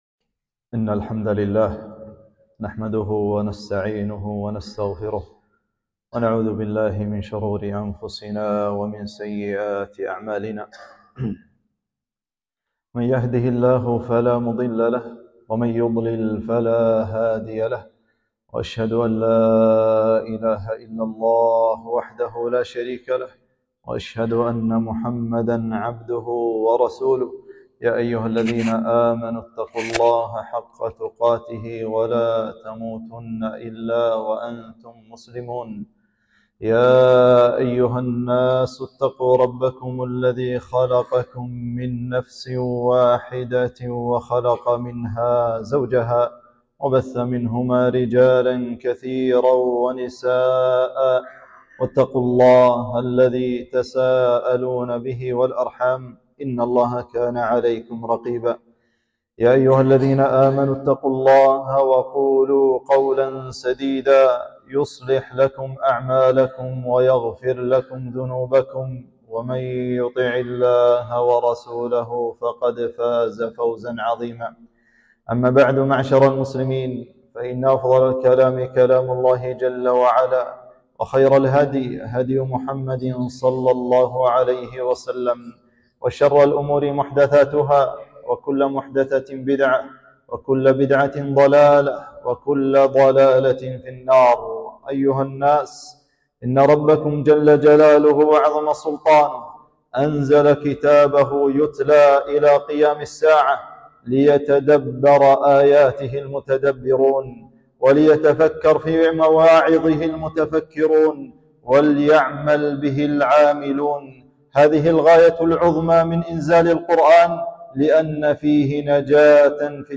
خطبة - تفسير سورة العصر